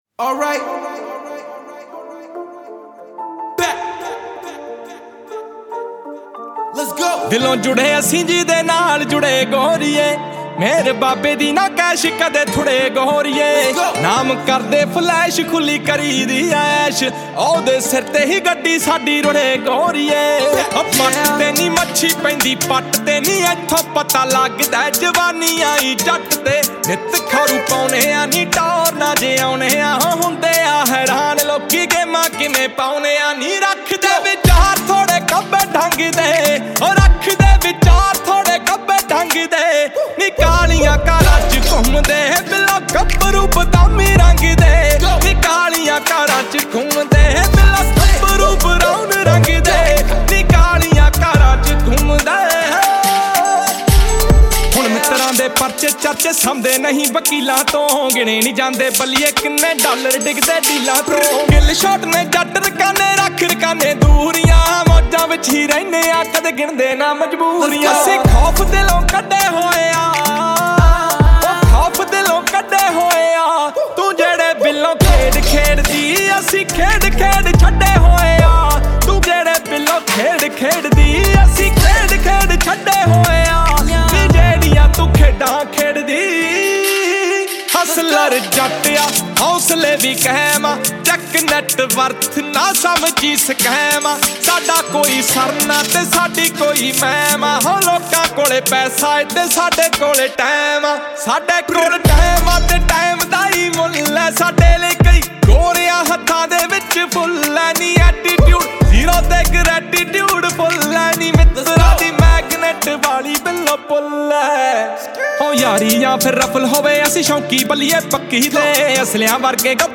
Punjabi Music